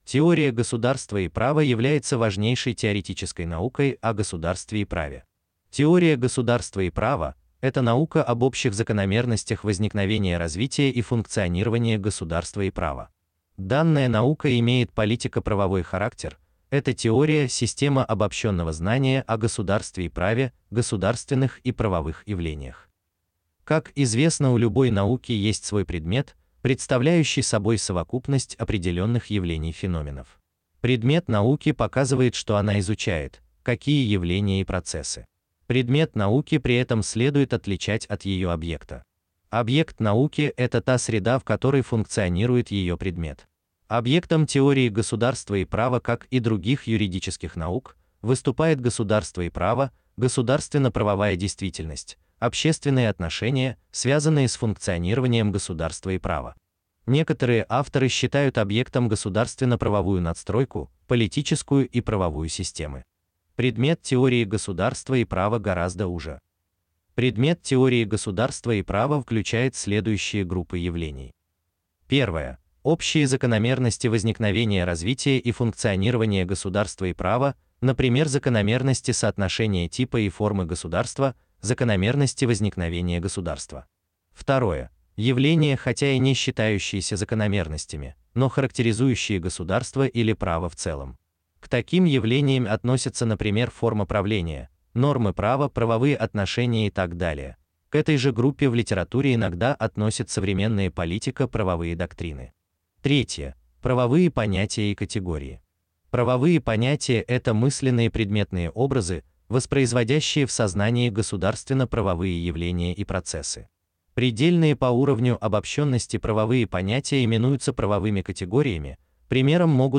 Аудиокнига Теория государства и права. Краткий курс лекций | Библиотека аудиокниг
Читает аудиокнигу Искусственный интеллект Ivan